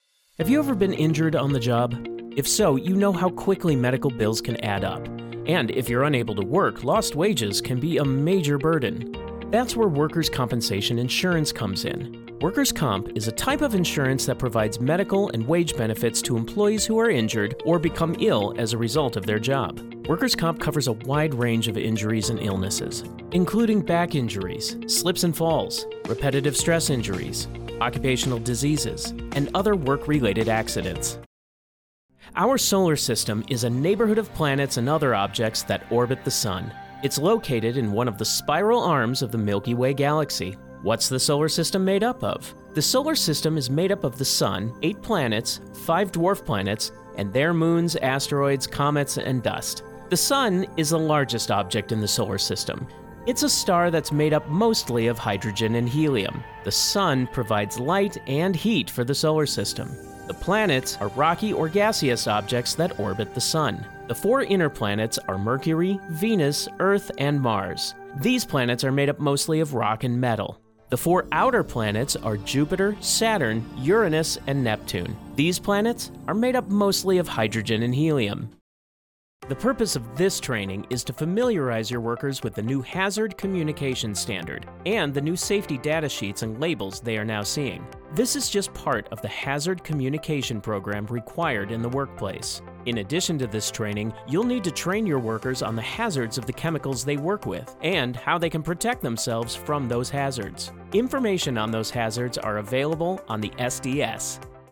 Explainer
English - Midwestern U.S. English